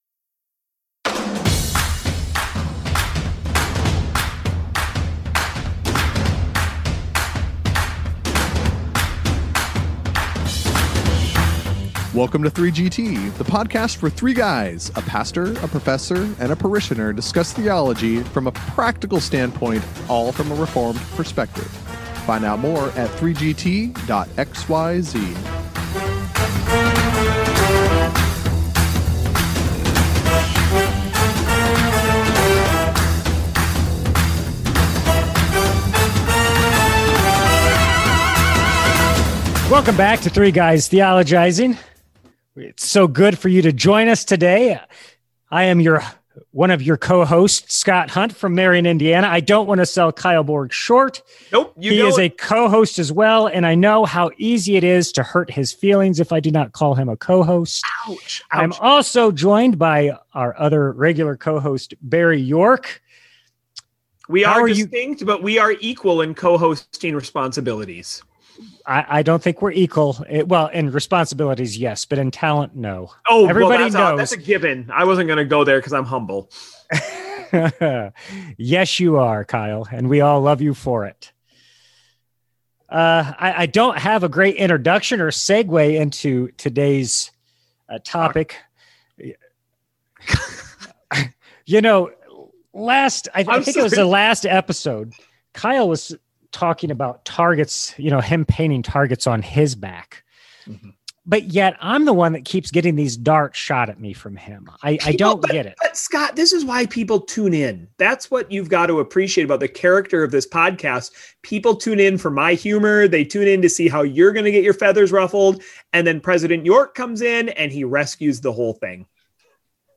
As usual, the pastor and parishioner go at it to build some dramatic tension for the podcast.